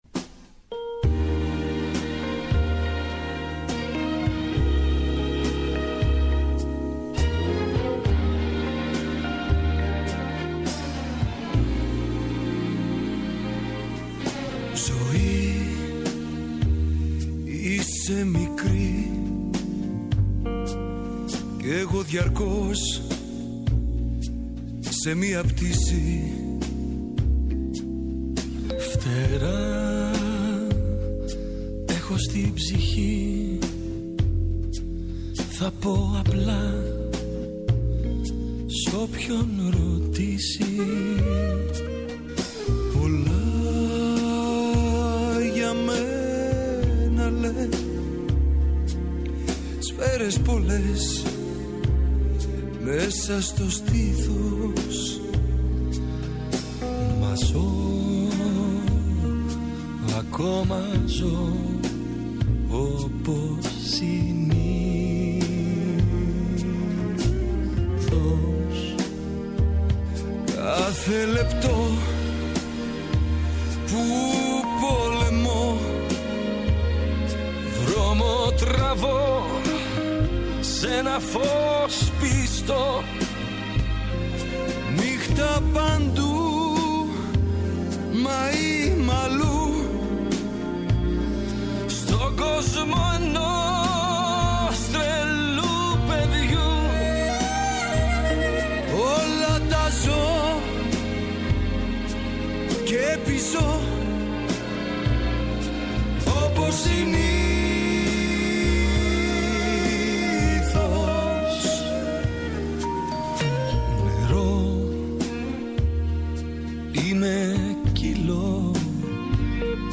σε ανταπόκριση από το Παρίσι